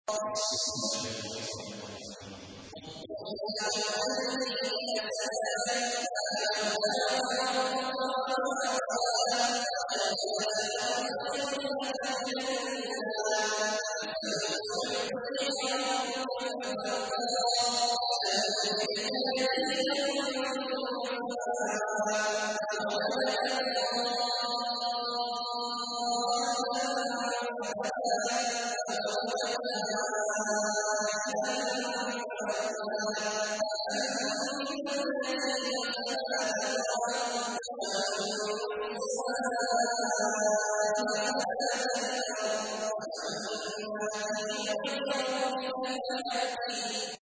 تحميل : 93. سورة الضحى / القارئ عبد الله عواد الجهني / القرآن الكريم / موقع يا حسين